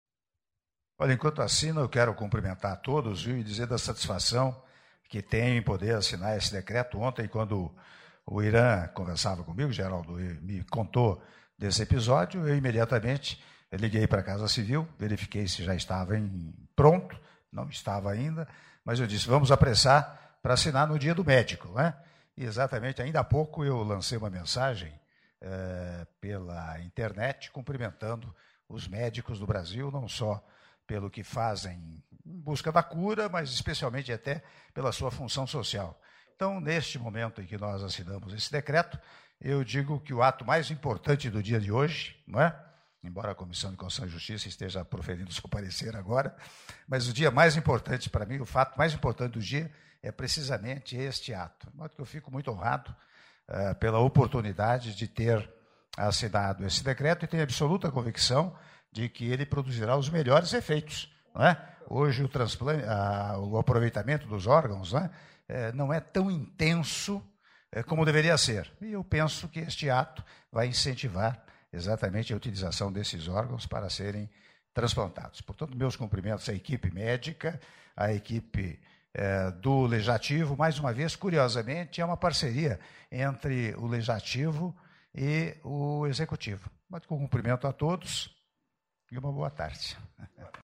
Áudio das palavras do Presidente da República, Michel Temer, durante assinatura do decreto que moderniza o Sistema Nacional de Transplante - Brasília/DF (01min30s) — Biblioteca